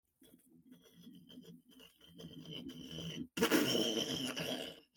volcano-eruption.mp3